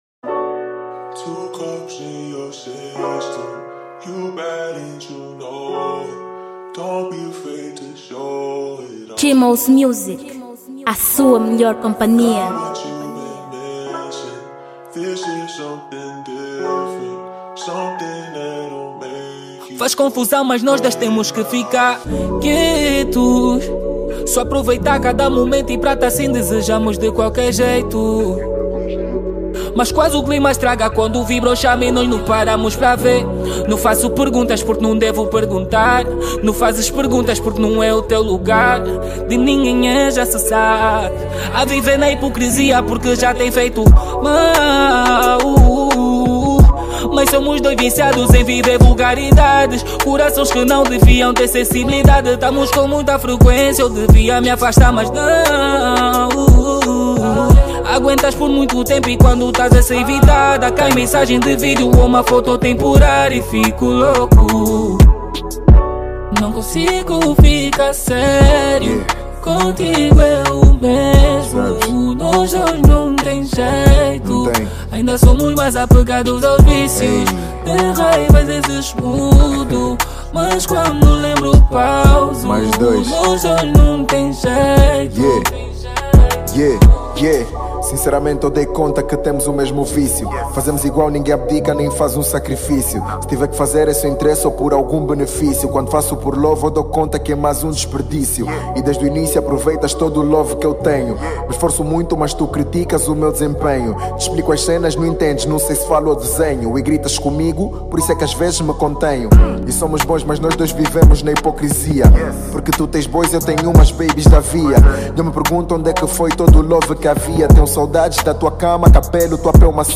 R&B BAIXAR DIREITO